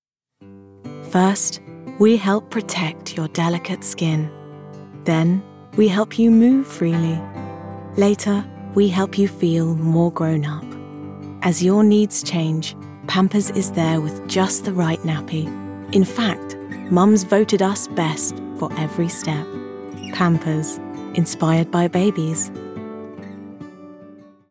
Voice Reel
Pampers - Soothing, Warm, Nurturing